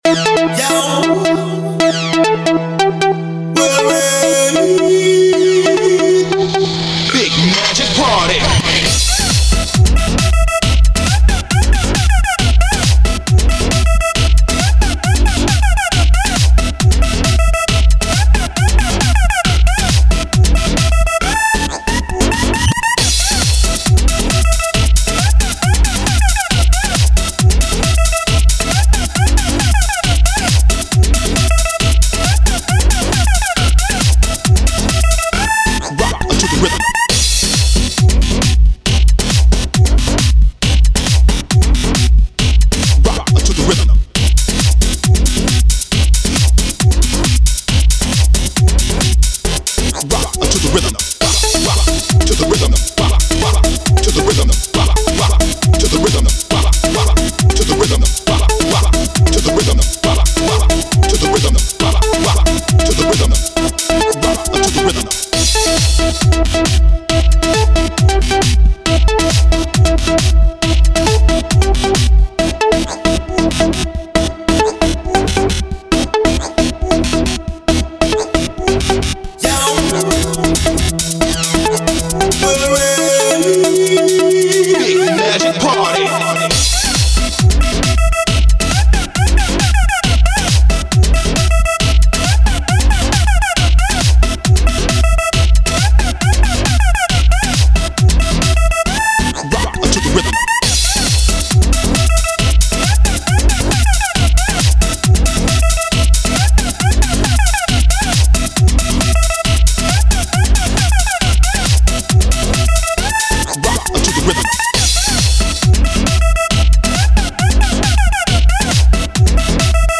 Всем любителям пищалок крутой трэк!!!
Club music